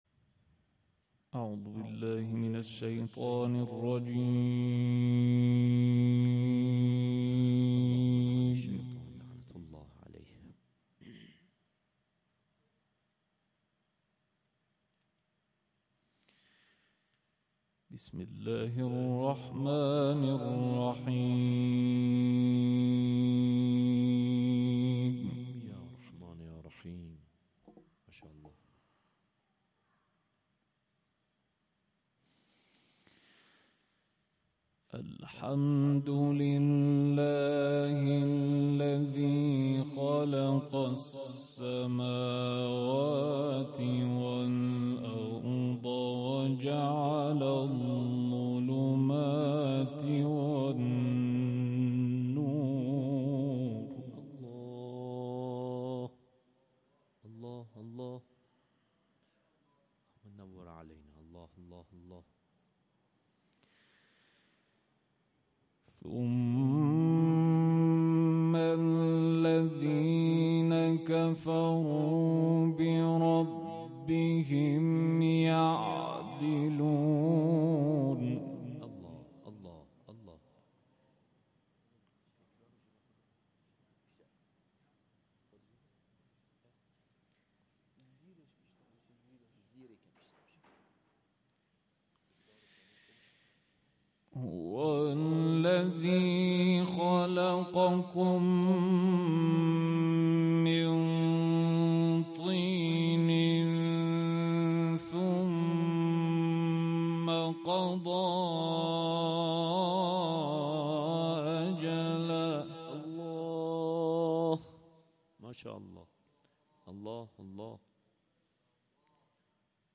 تلاوت‌